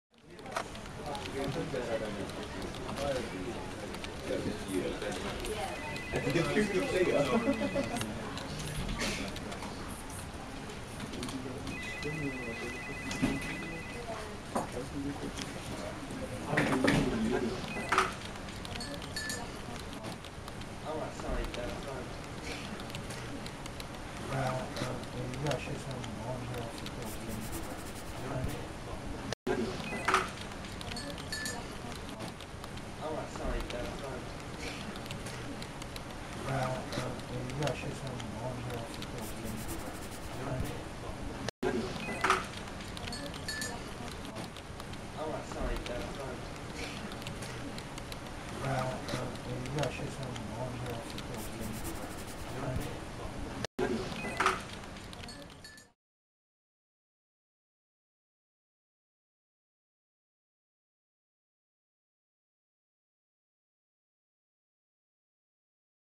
جلوه های صوتی
دانلود آهنگ اداره 2 از افکت صوتی طبیعت و محیط